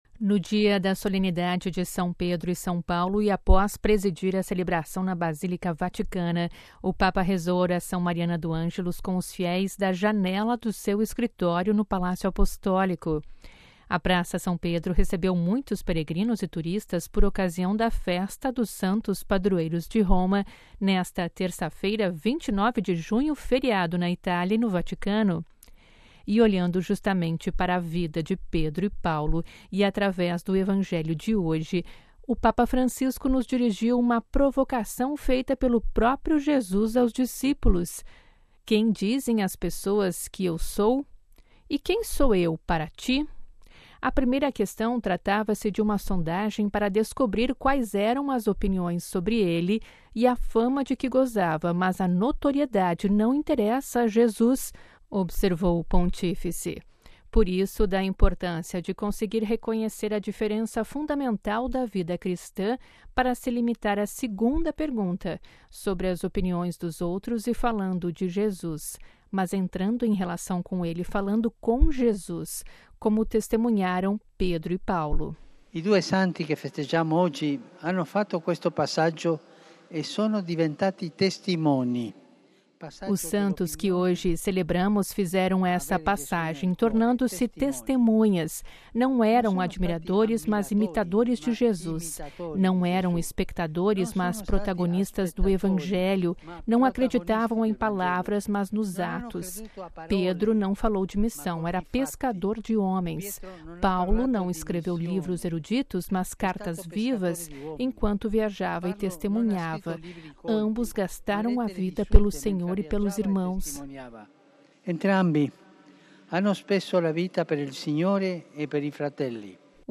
Ouça a reportagem com a voz do Papa e compartilhe